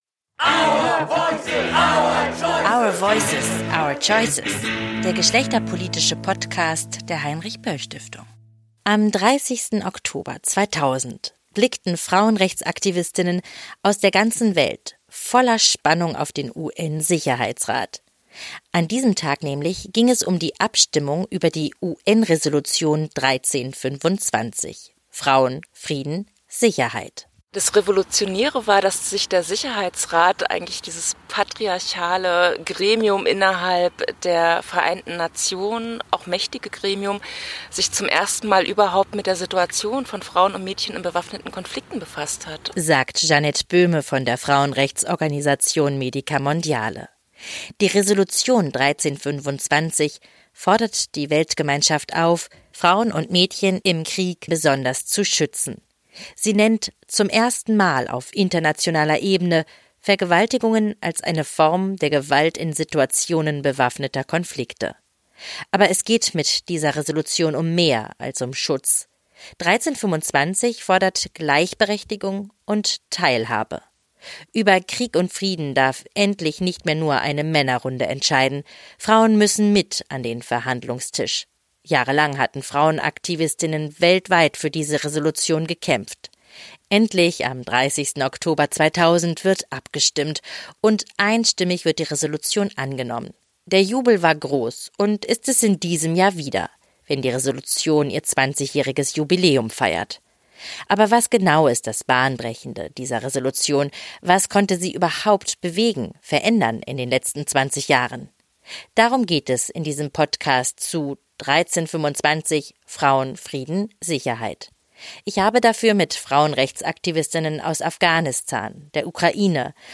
Zum 20-jährigen Jubiläum schauen wir uns an, was sie verändern konnte, für Frauen und Mädchen in Kriegs- und Nachkriegsgesellschaften. Wir sprechen mit Frauenrechtsaktivistinnen aus Afghanistan, der Ukraine, Deutschland und Montenegro.